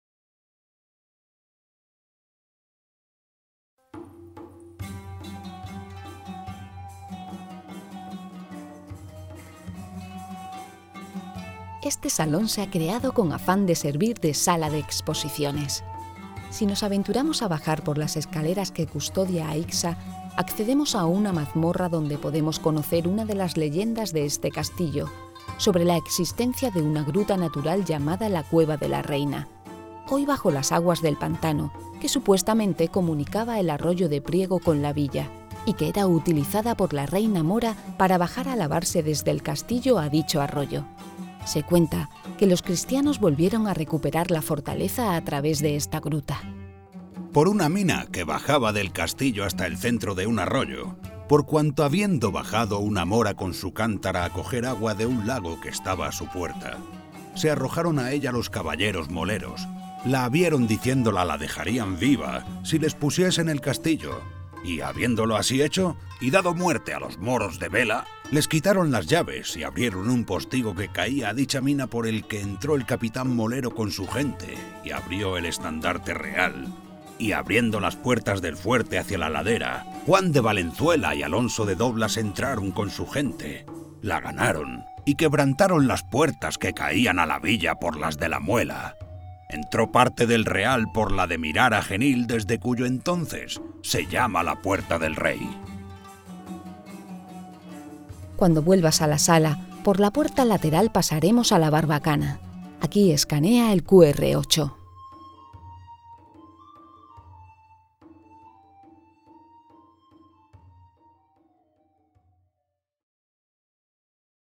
Ruta audioguiada